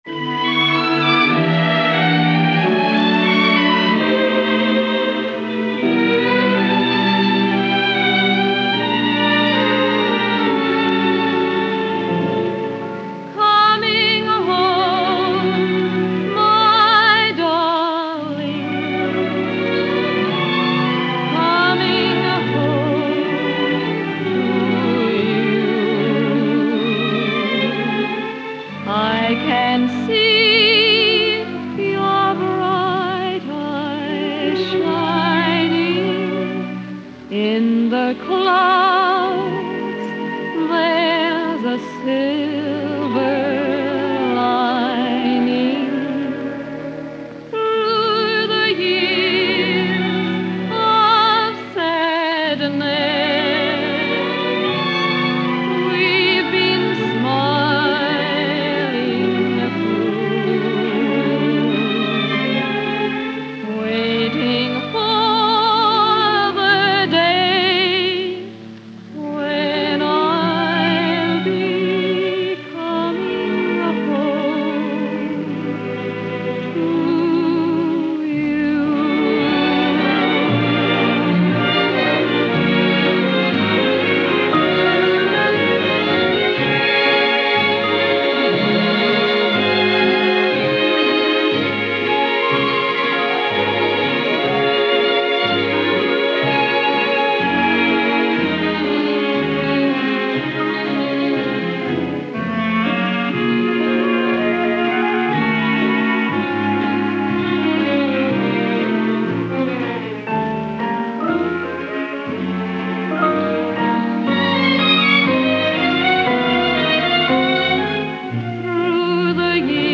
Genre: Traditional Pop, Vocal Jazz, Easy Listening